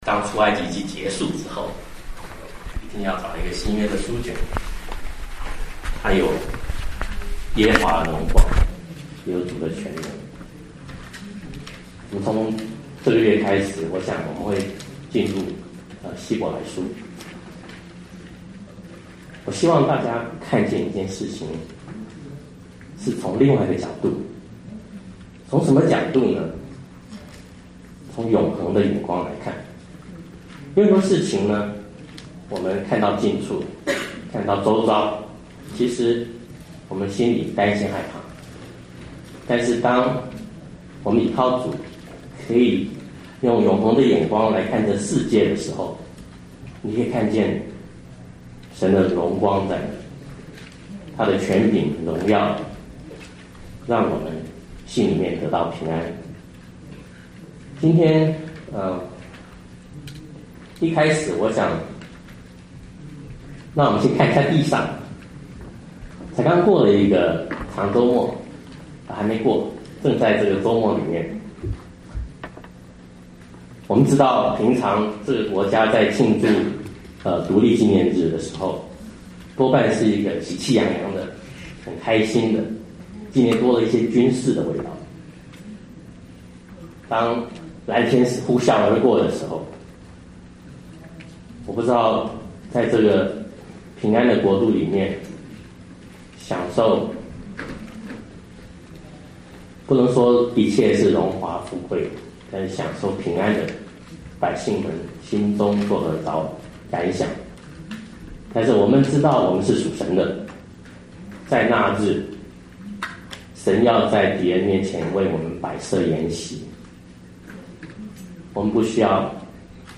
牛頓國語崇拜